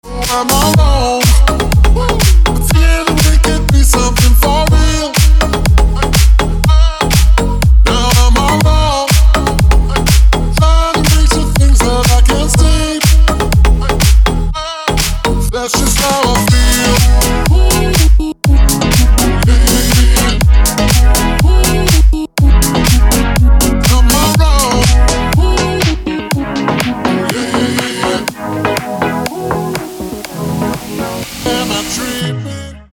• Качество: 256, Stereo
мужской вокал
deep house
dance
Electronic
EDM
club